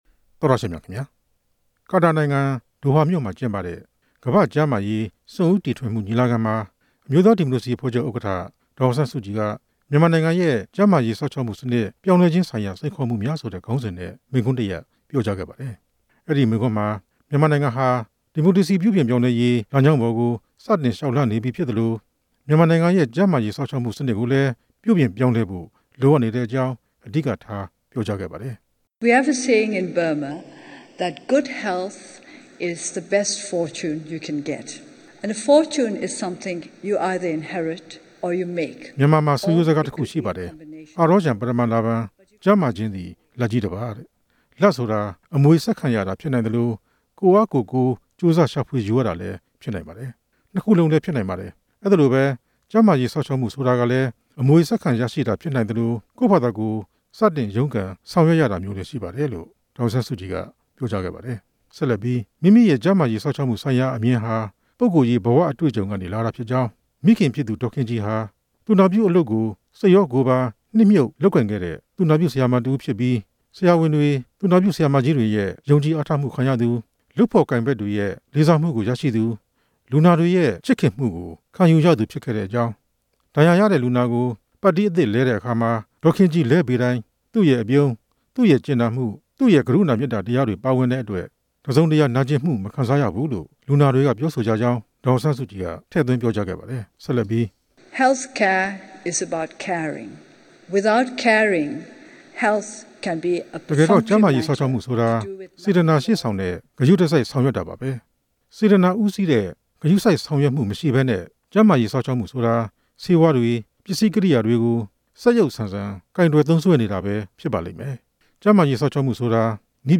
ကာတာနိုင်ငံ ဒိုဟာမြို့မှာ ကျင်းပနေတဲ့ ကမ္ဘာ့ကျန်းမာရေး စွန့်ဦးတီထွင်မှု ညီလာခံမှာ မြန်မာနိုင်ငံ ကျန်းမာရေးစောင့်ရှောက်မှု ပြုပြင်ပြောင်းလဲရေးဆိုင်ရာ မိန့်ခွန်းတစ်ရပ် အင်္ဂါနေ့က ဟောပြောခဲ့စဉ်အတွင်း ဒေါ်အောင်ဆန်းစုကြည်က အလေးပေးပြောကြားခဲ့တာ ဖြစ်ပါတယ်။